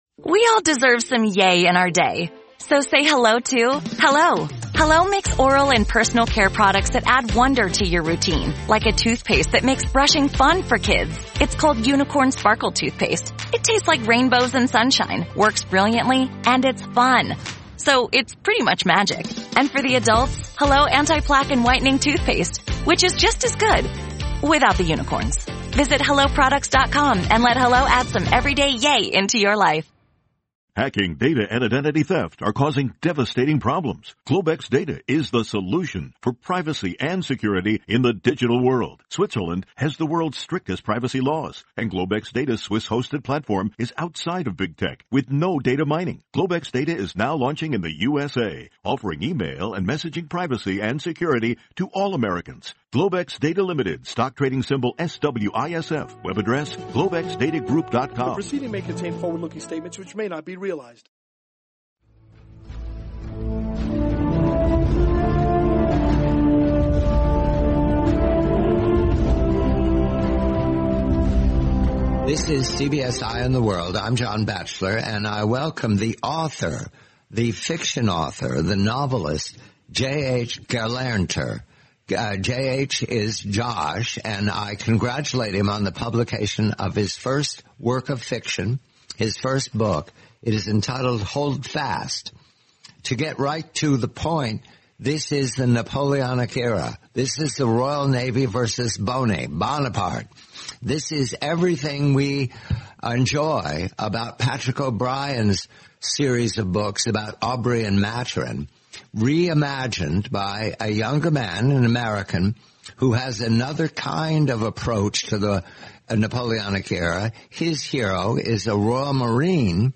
Napoleonic-era espionage. The complete, twenty-minute interview.